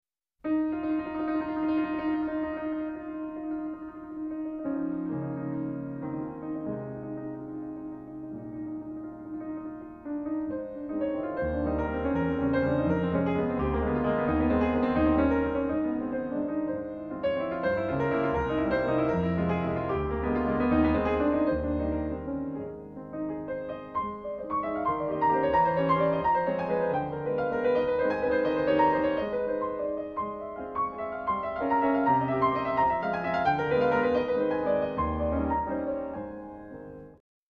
A flat major